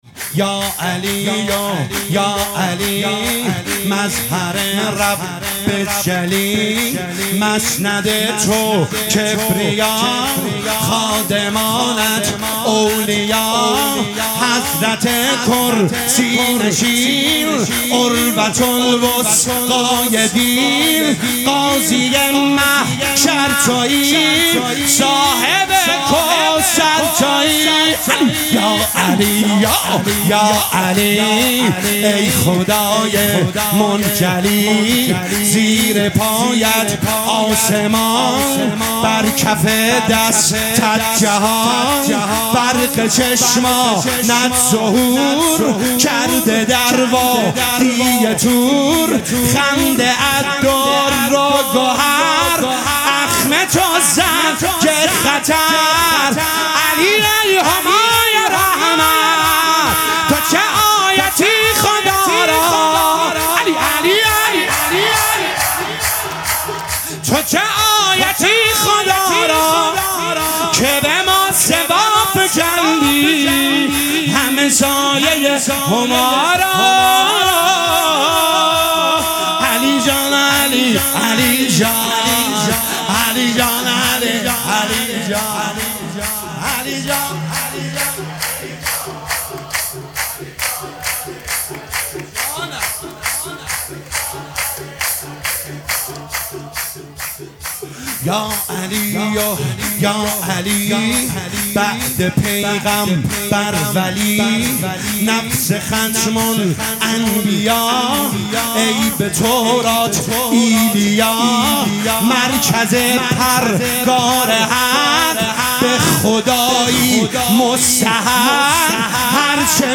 ولادت امام رضا(ع) 1403